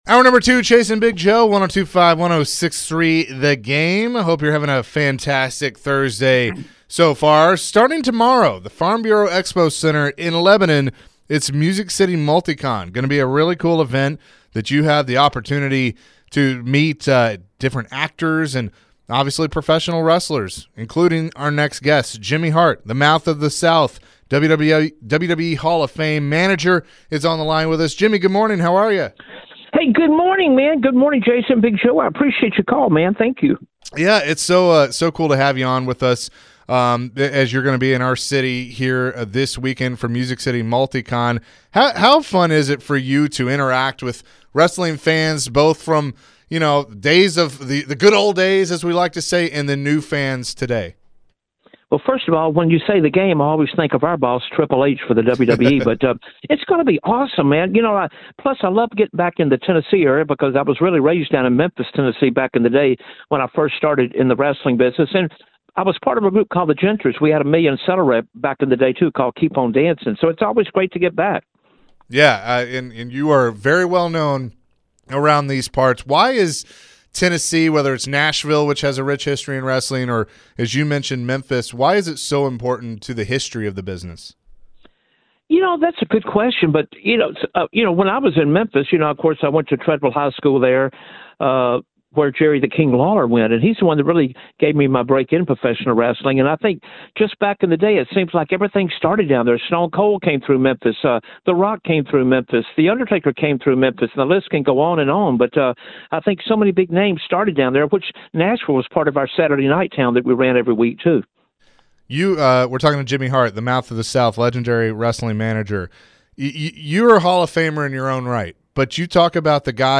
Later in the conversation, Jimmy talked about the wrestling business.